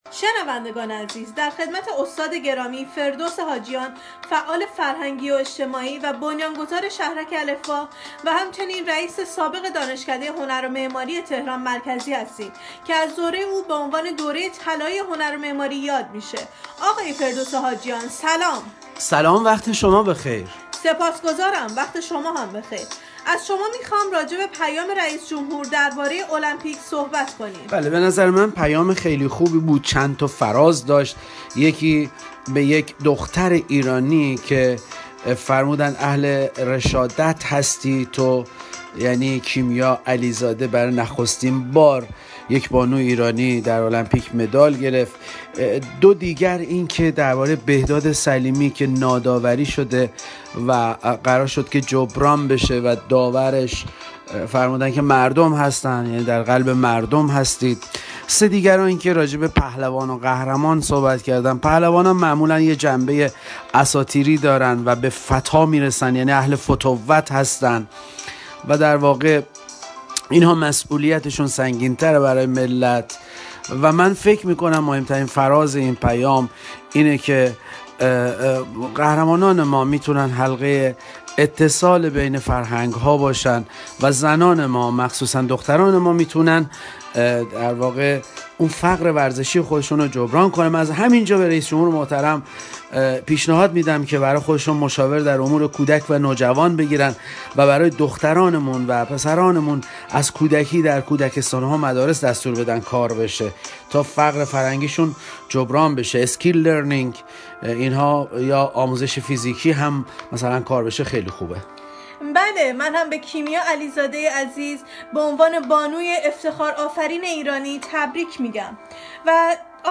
فایل صوتی این گفتگو را